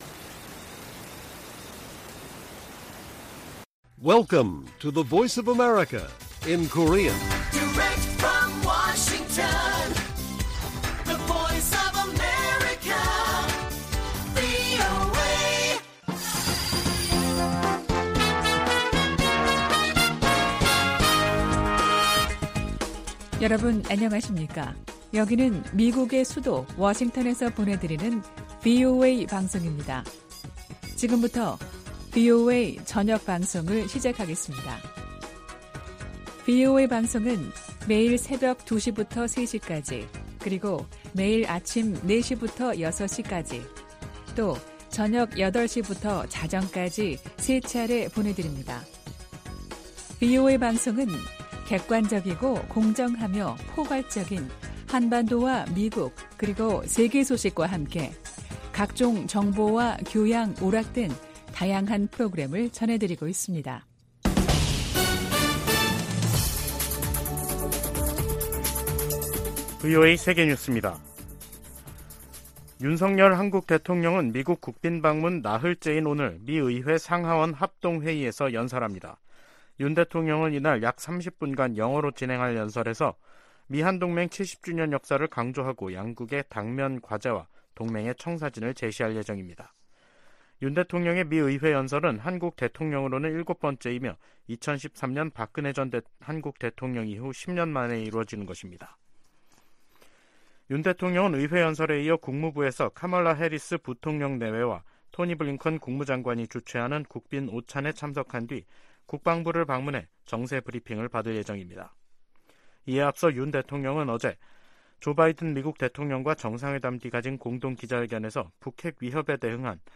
VOA 한국어 간판 뉴스 프로그램 '뉴스 투데이', 2023년 4월 27일 1부 방송입니다. 조 바이든 미국 대통령과 윤석열 한국 대통령이 26일 백악관 회담에서 '워싱턴 선언'을 채택하고, 미한 핵협의그룹을 창설하기로 했습니다. 전문가들은 이번 정상회담에서 양국 관계가 '글로벌 포괄적 전략동맹'으로 격상을 확인했다고 평가했습니다.